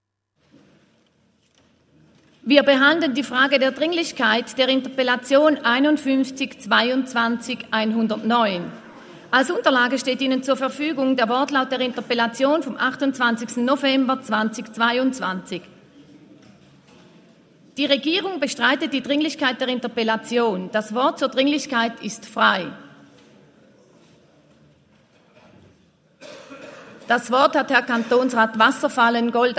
Session des Kantonsrates vom 28. bis 30. November 2022